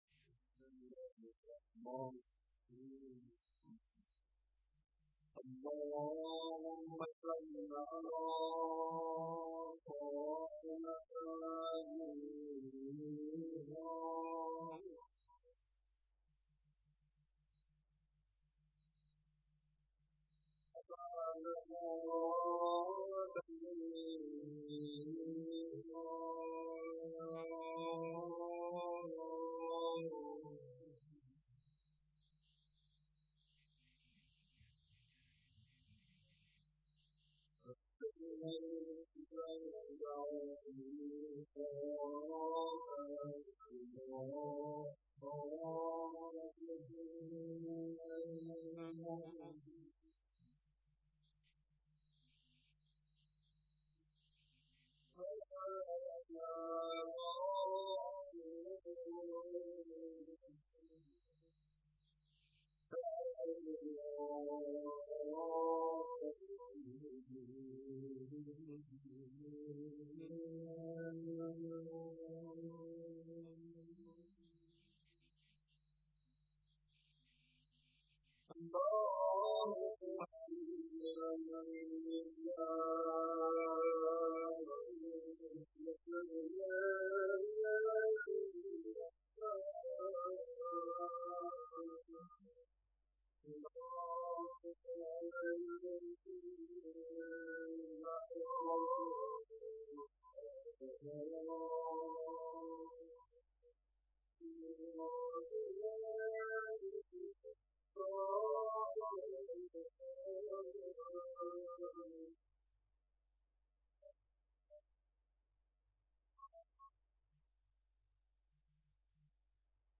مداحی جناب آقای محمد رضا طاهری